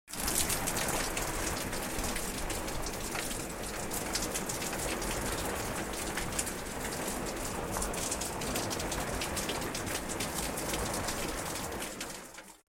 دانلود آهنگ باران 18 از افکت صوتی طبیعت و محیط
دانلود صدای باران 18 از ساعد نیوز با لینک مستقیم و کیفیت بالا
جلوه های صوتی